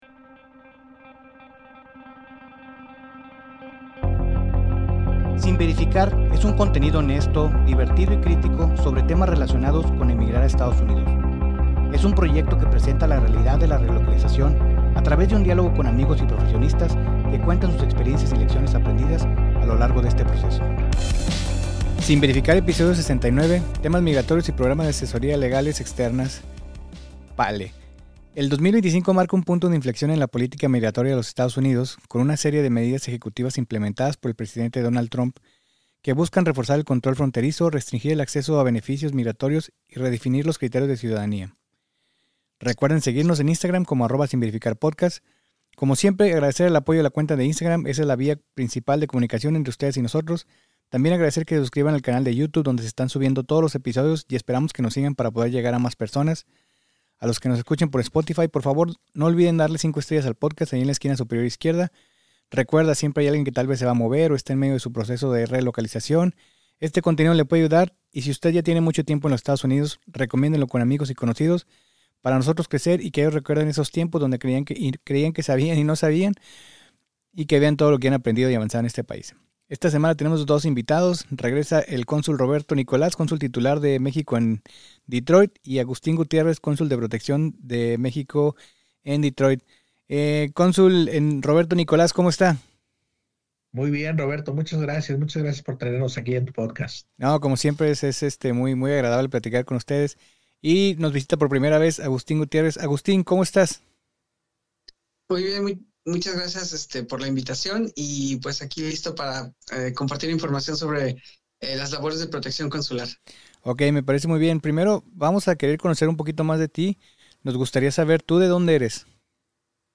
Sin Verificar es un contenido honesto, divertido y crítico sobre temas que pueden resultar de interés a mexicanos radicados en Estados Unidos. Es un proyecto que presenta la realidad de la relocalización a través de un diálogo abierto con amigos y profesionistas que cuentan en voz propia las experiencias buenas y malas, así como las lecciones aprendidas desde el momento de decidir emigrar a Los Estados Unidos.